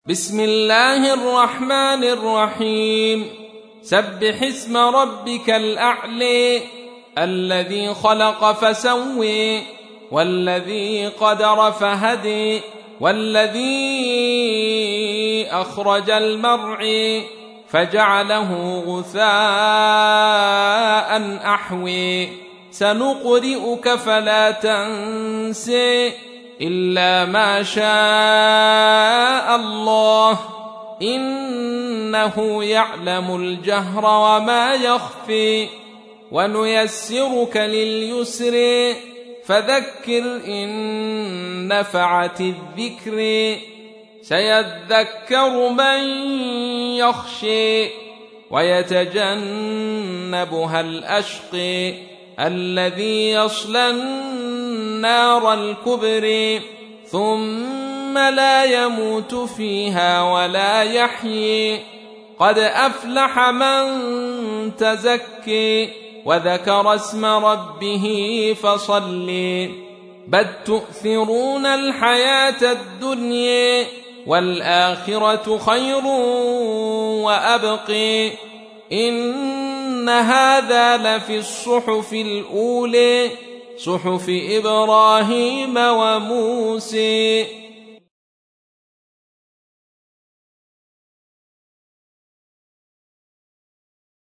87. سورة الأعلى / القارئ